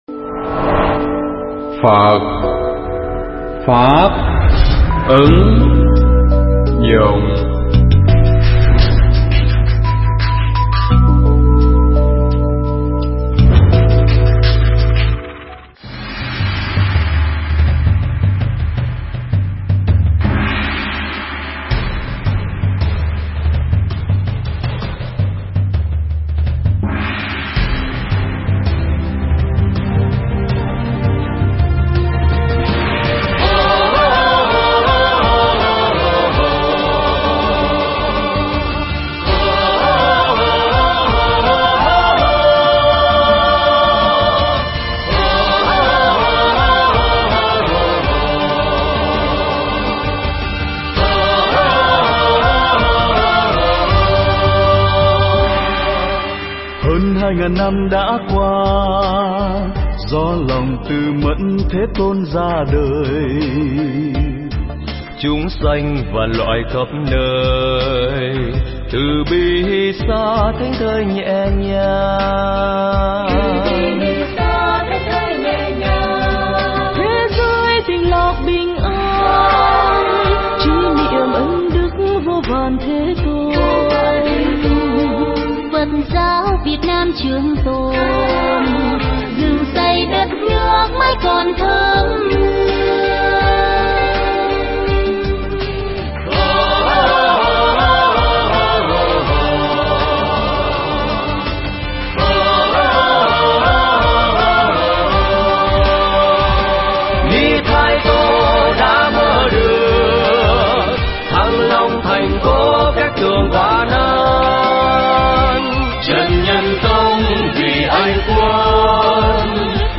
Pháp thoại
nhân ngày sinh của Đức Phật tại Quảng Trường 2/4 - 46 Trần Phú, TP. Nha Trang